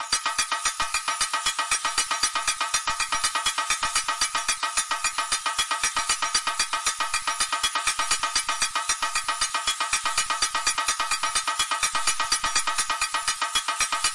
标签： 运输 马车 舞台剧
声道立体声